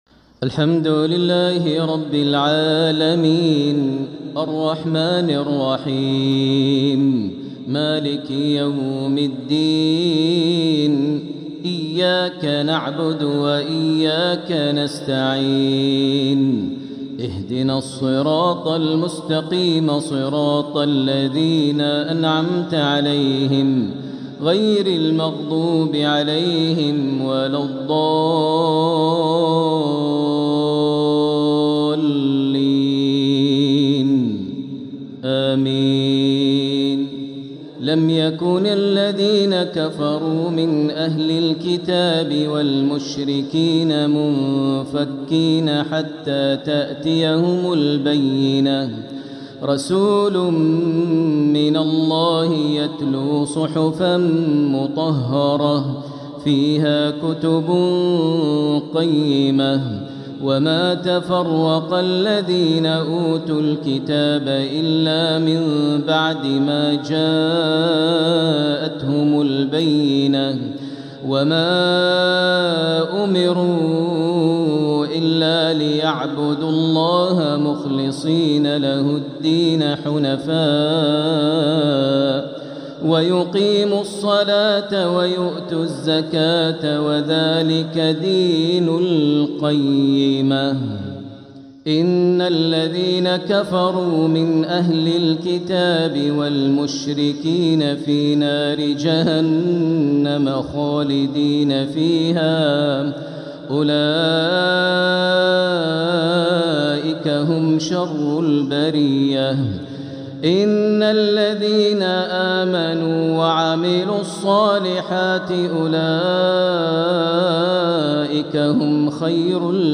الإصدار القرآني المميز | لفروض شهر جمادى الآخرة لعام 1446هـ | لفضيلة الشيخ د. ماهر المعيقلي > سلسلة الإصدارات القرآنية للشيخ ماهر المعيقلي > الإصدارات الشهرية لتلاوات الحرم المكي 🕋 ( مميز ) > المزيد - تلاوات الحرمين